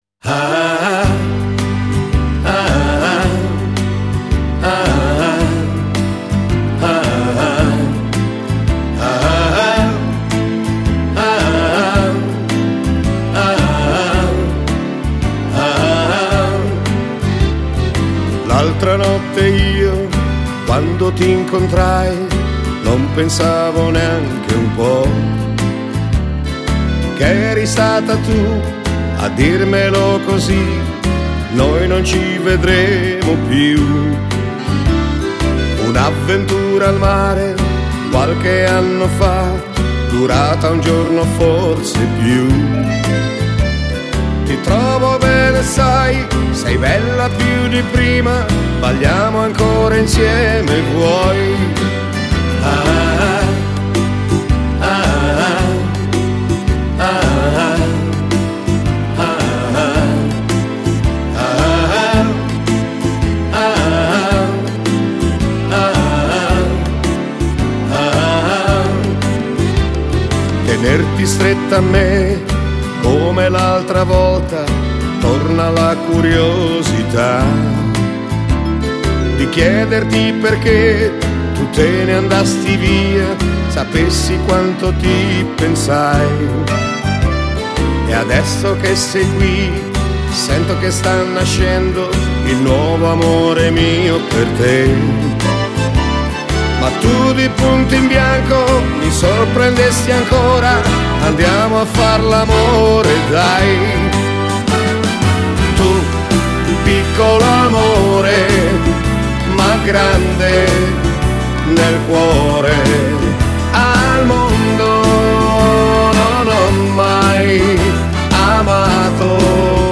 Genere: Moderato